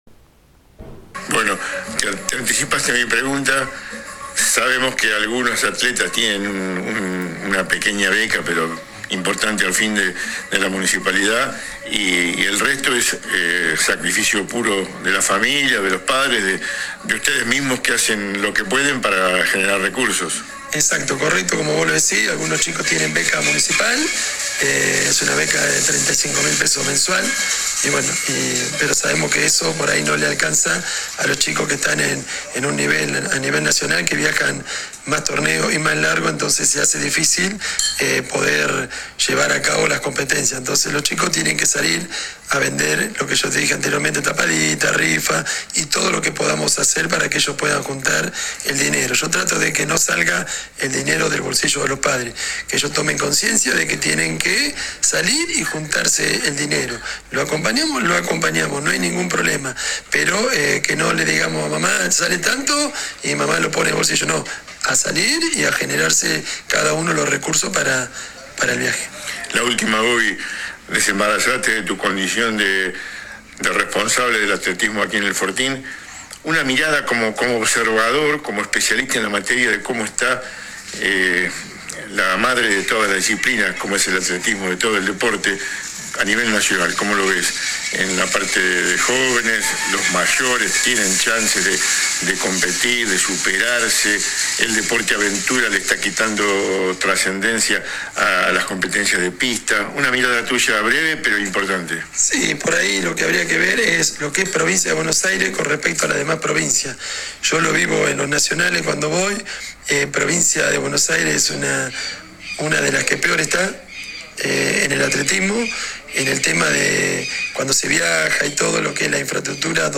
AUDIOS DE LAS ENTREVISTAS